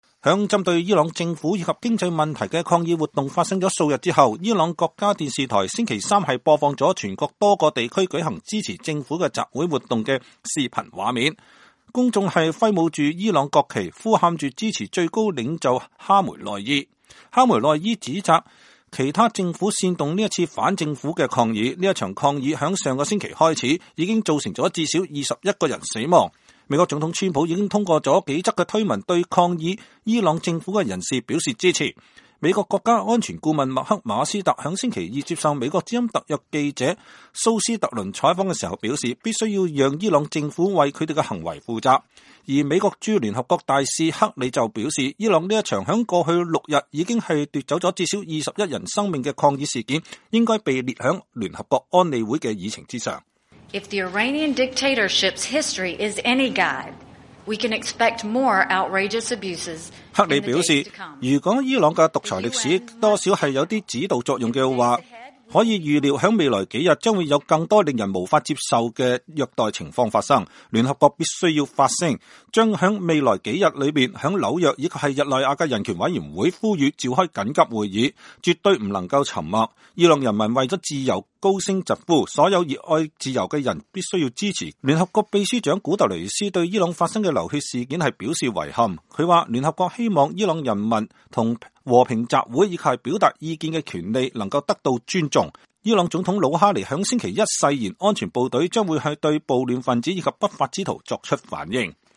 人群揮舞著伊朗國旗，呼喊著支持最高領袖哈梅內伊。